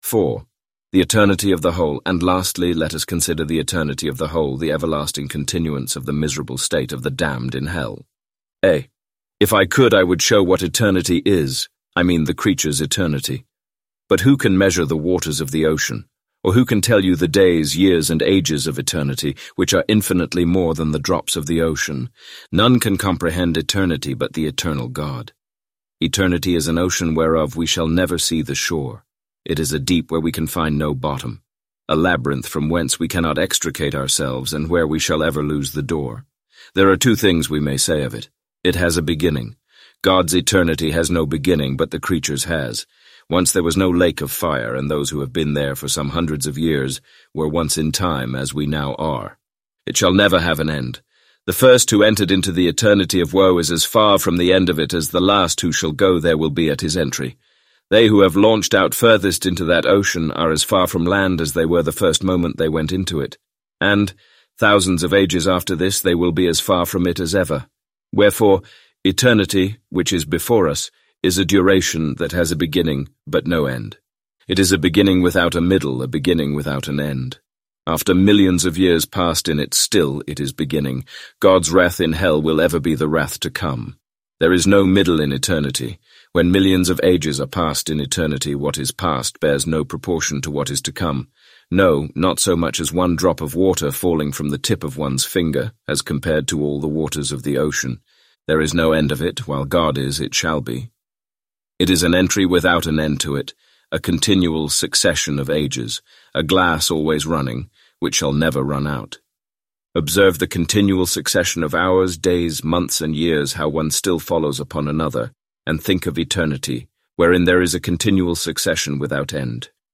Complete Audiobook